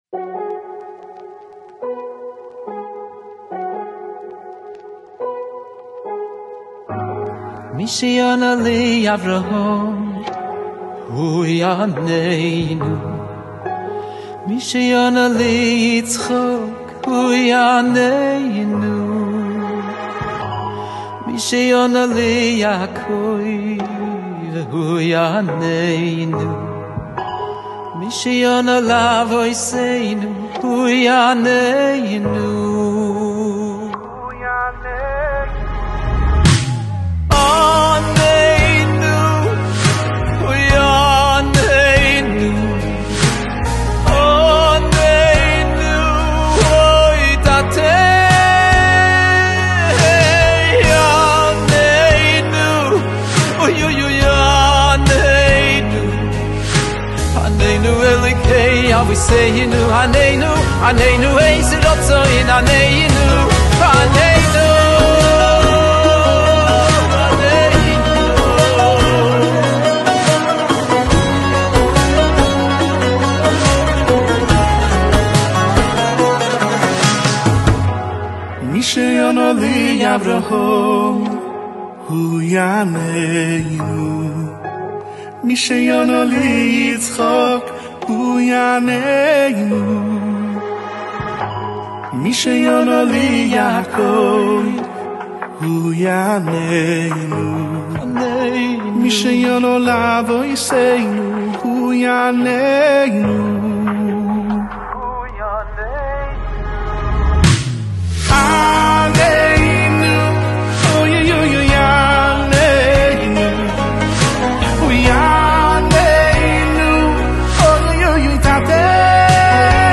דואט חדש חסידי ומרגש